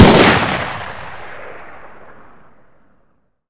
svd_fire1.wav